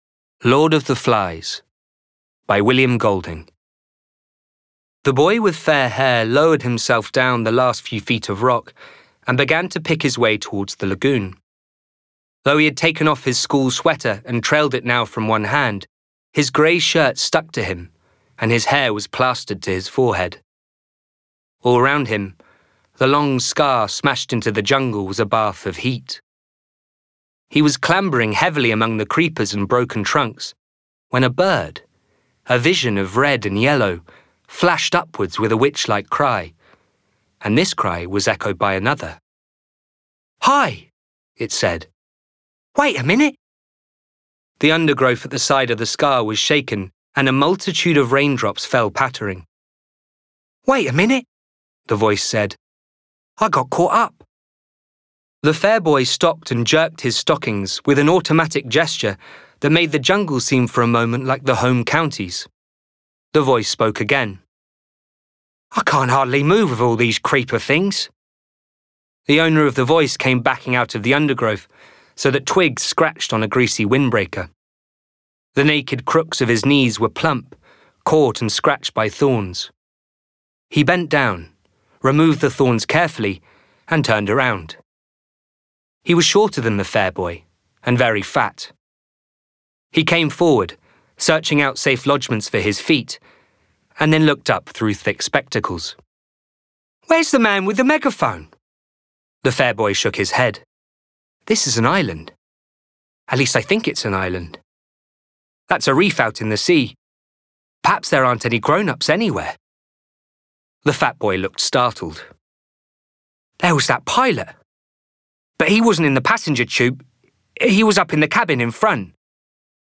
Gender Male
Audio Books